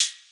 HiHat (9).wav